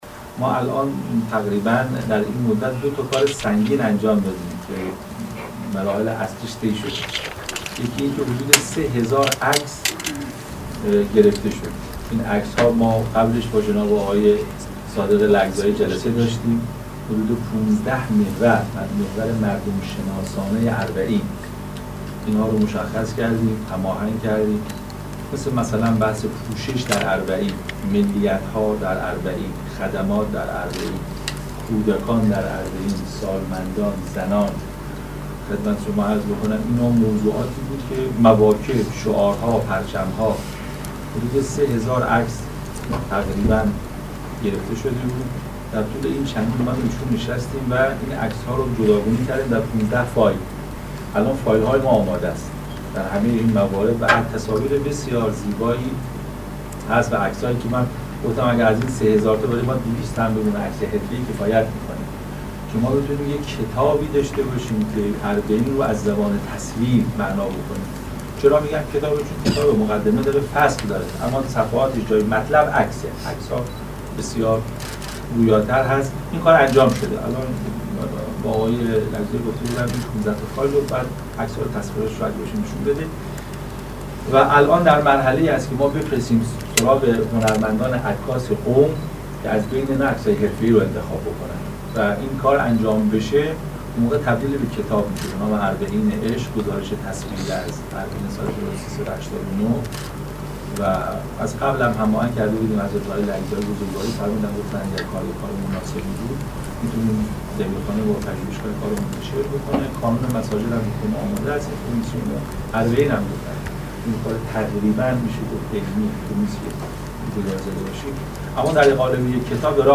به گزارش خبرنگار خبرگزاری رسا، نشست علمی پدیدار شناسی اربعین ویژه گزارش تحلیل گروه اعزامی به راهپیمایی اربعین در سالن همایش های انجمن های علمی حوزه برگزار شد.